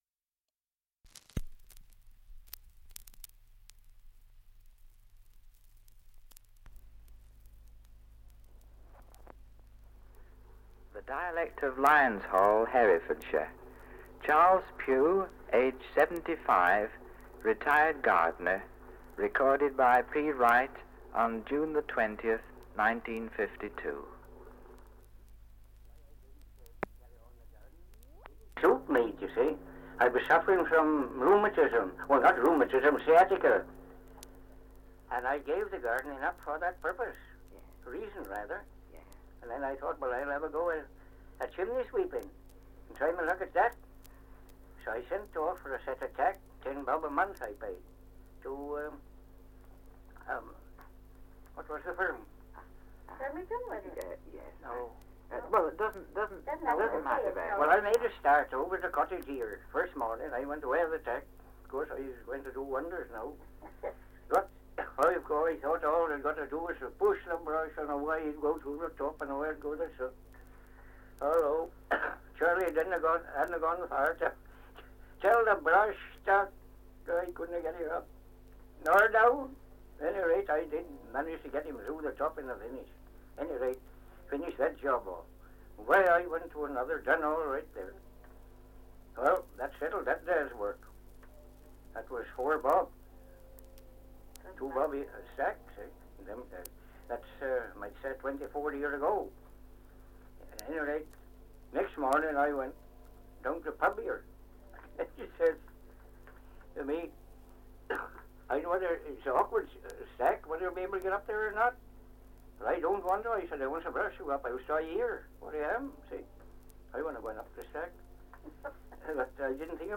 Survey of English Dialects recording in Lyonshall, Herefordshire
78 r.p.m., cellulose nitrate on aluminium